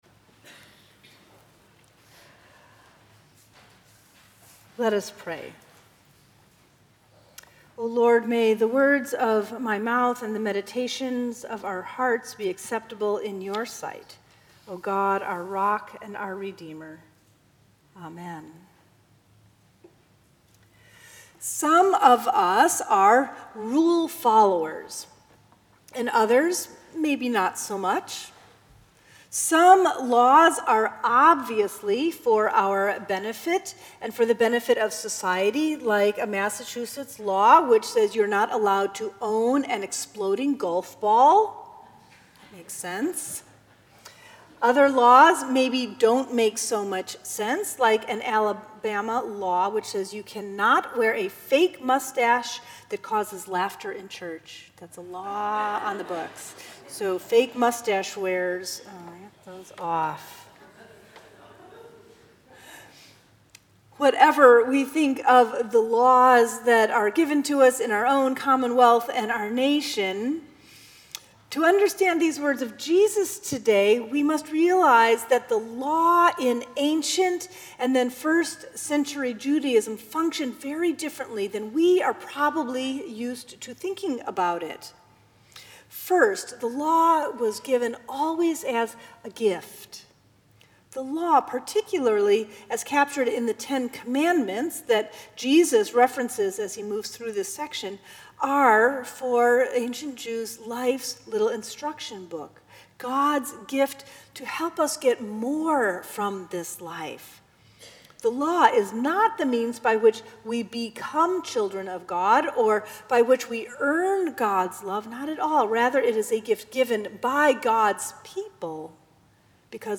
February 12, 2017 Sixth Sunday after Epiphany